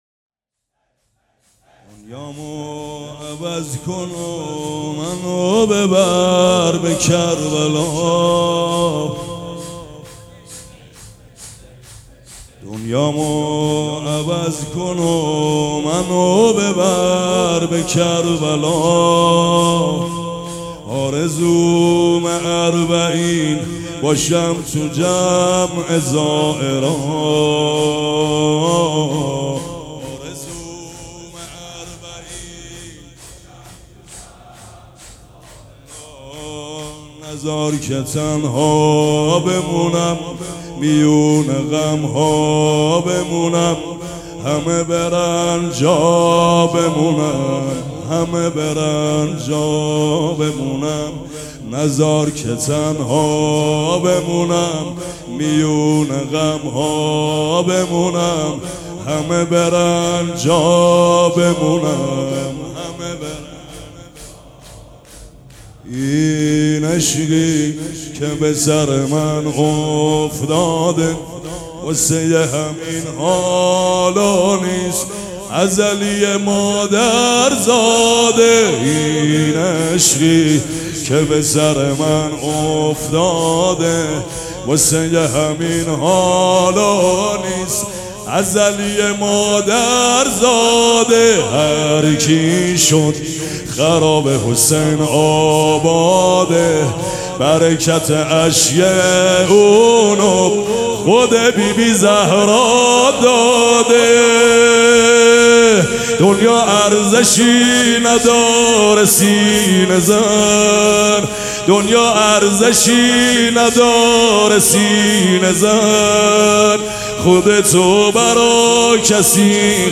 شور
مداح
مراسم عزاداری شب اول